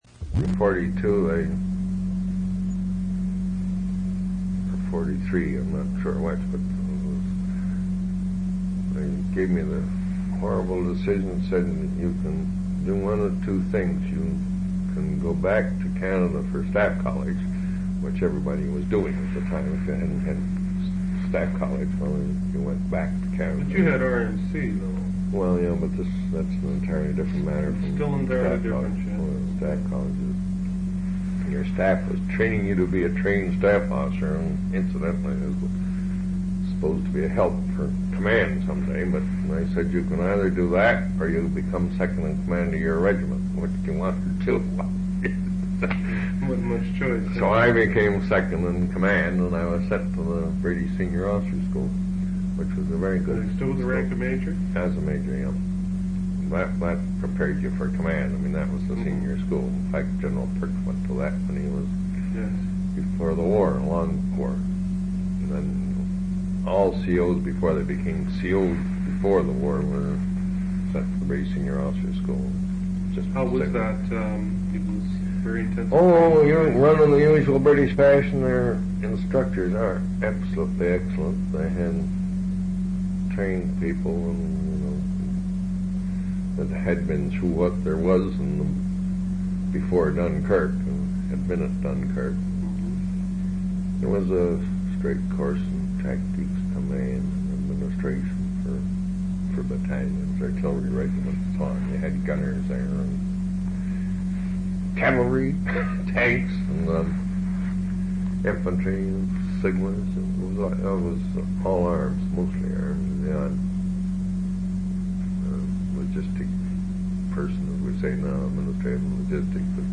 An interview/narrative of Cameron B. Ware's experiences during World War II. Major-General Ware, D.S.O. served with Princess Patricia's Canadian Light Infantry.